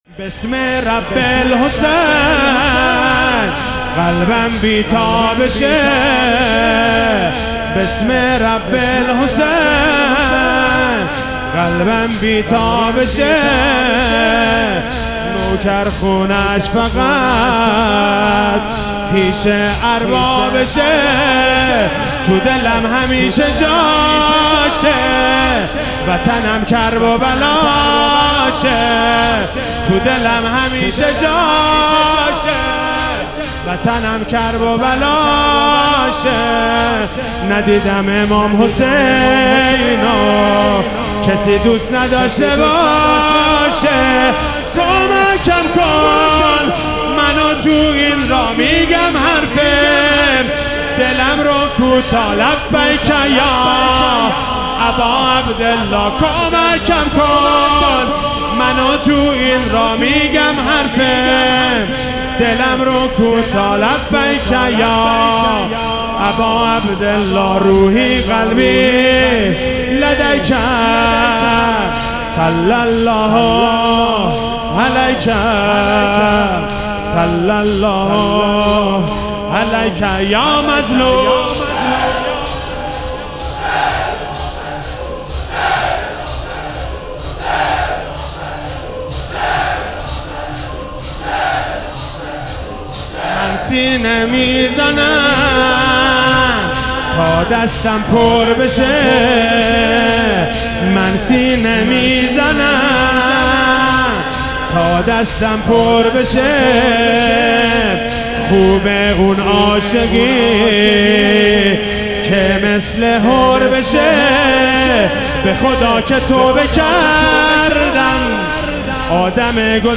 شهادت امام جواد علیه السلام97 - شور - بسم رب الحسین قلبم بی تابشه
شهادت امام جواد علیه السلام